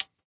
switch_click_light.ogg